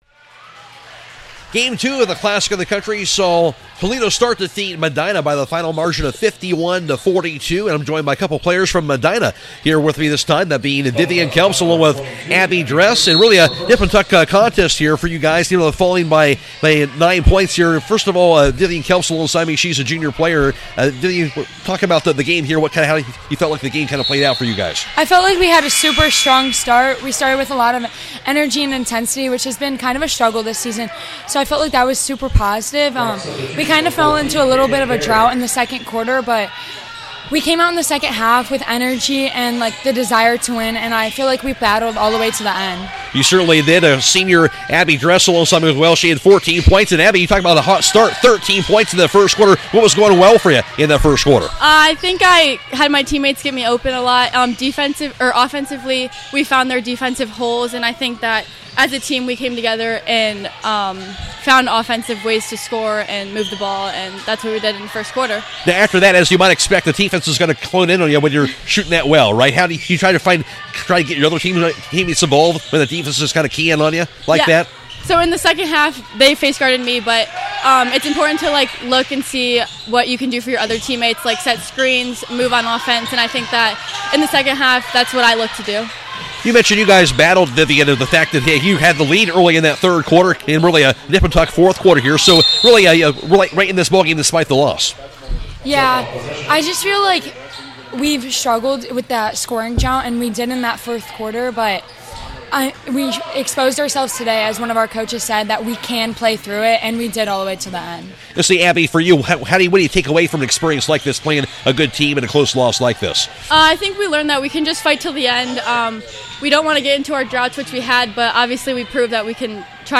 2026 CLASSIC – MEDINA PLAYER INTERVIEWS